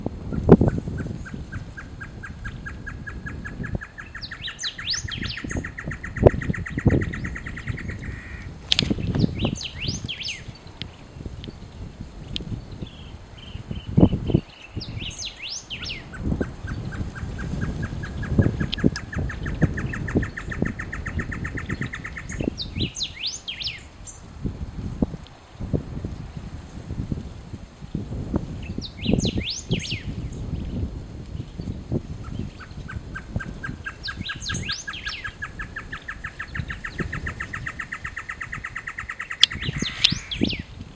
Chororó (Taraba major)
Nombre en inglés: Great Antshrike
Localidad o área protegida: Trancas
Condición: Silvestre
Certeza: Vocalización Grabada